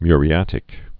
(myrē-ătĭk)